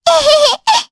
Luna-Vox-Laugh_jp.wav